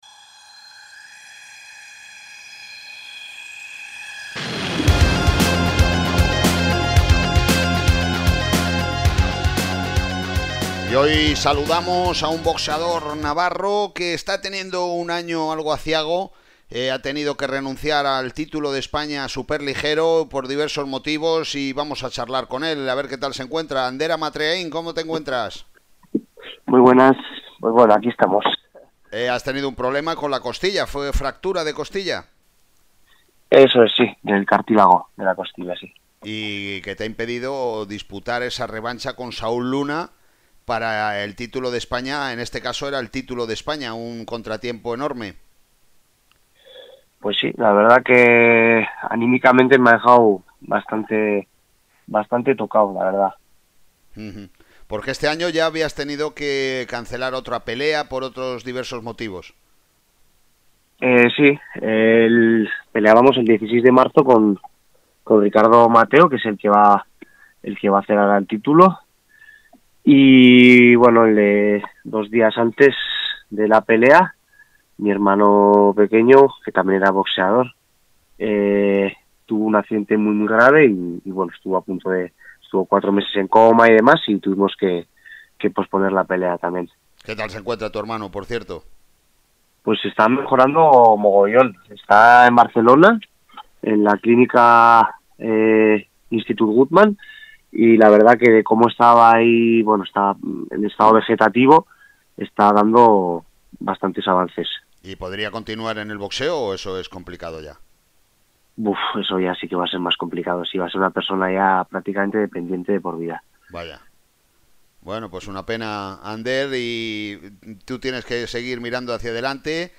entrevistados radiofónicamente